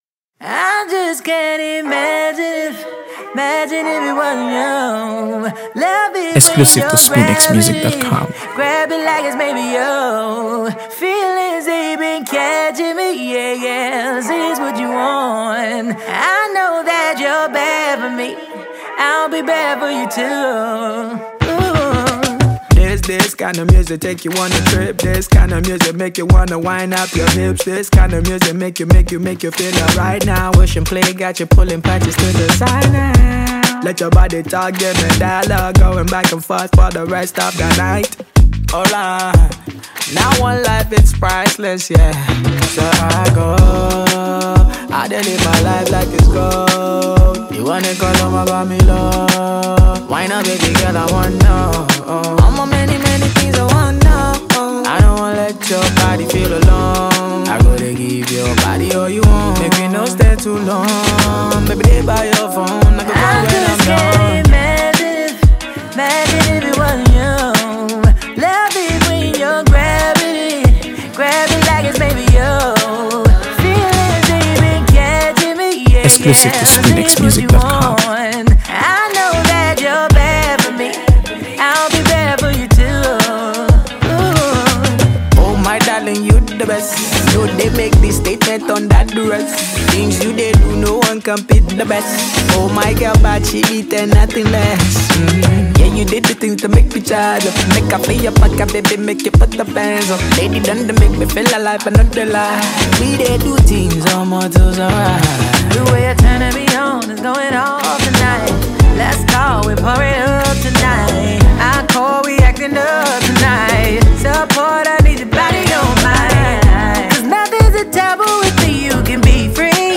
AfroBeats | AfroBeats songs
whose soulful voice adds depth and emotion to the song.